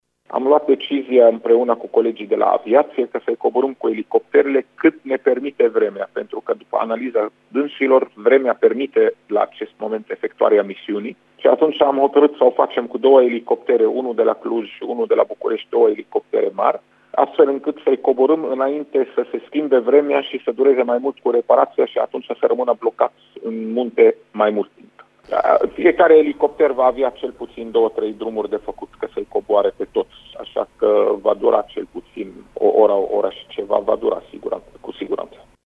Secretarul de stat în MAI, medicul Raed Arafat, a declarat pentru RTM că fiecare elicopter va avea de făcut câte două-trei drumuri pentru a-i coborî pe toți turiștii , astfel că acțiunea de intervenție va mai dura: